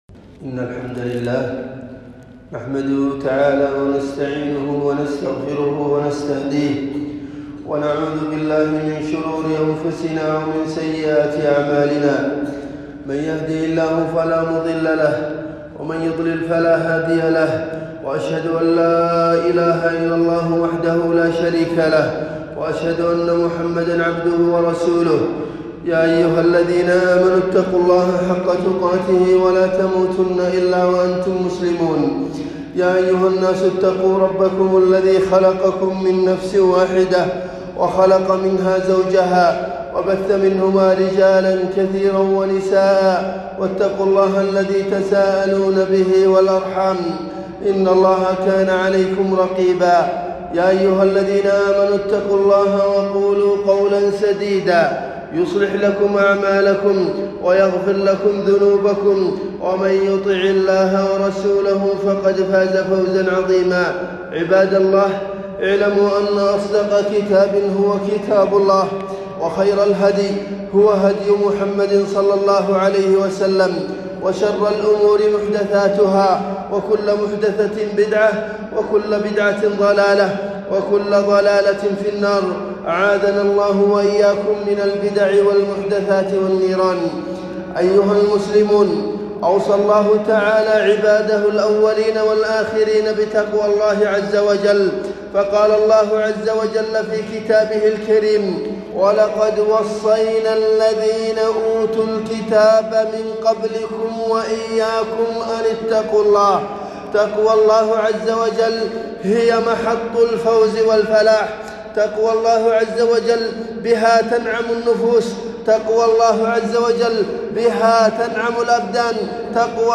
خطبة الجمعة - الخوف من اللَّه عزوجل